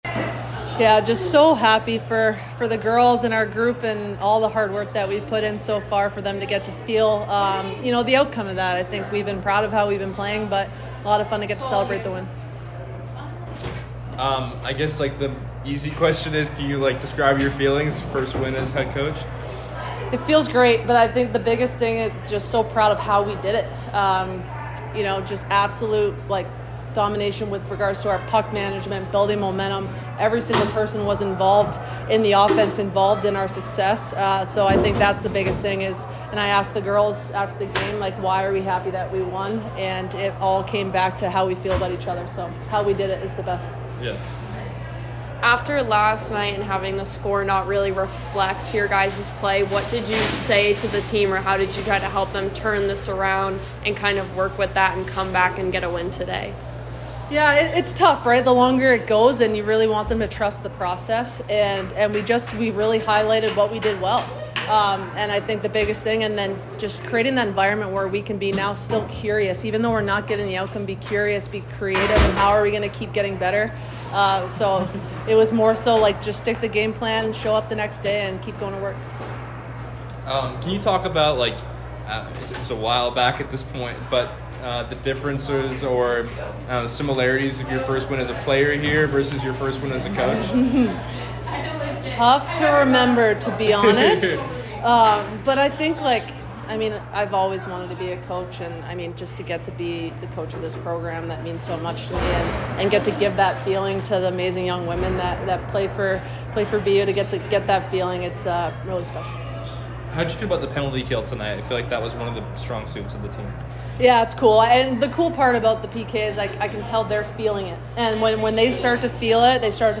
Women's Ice Hockey / Syracuse Postgame Interview (10-21-23)